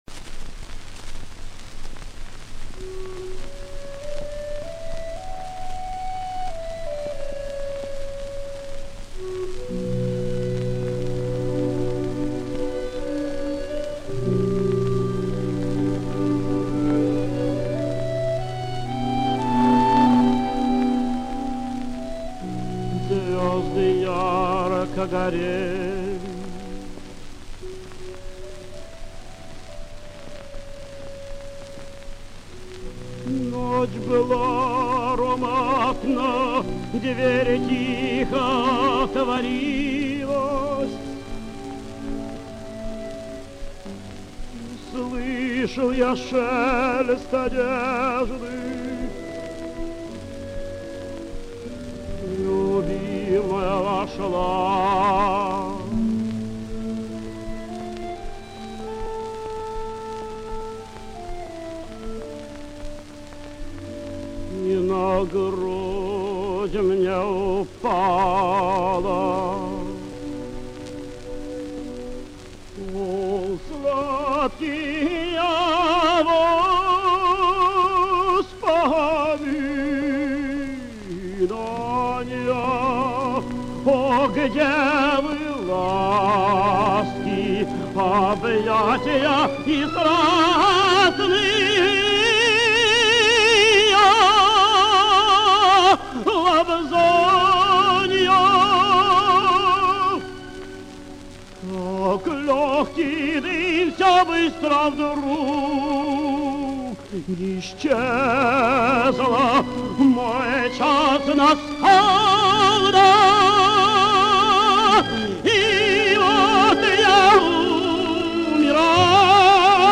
Гефт Бори́с О́сипович [1902, место рождения неизвестно – 1966, Ленинград (ныне Санкт-Петербург); похоронен на Преображенском еврейском кладбище], российский певец (тенор), заслуженный артист РСФСР (1939).
Ария Каварадосси. Оркестр МАЛЕГОТа. Дирижёр Б. Э. Хайкин. Исполняет Б. О. Гефт.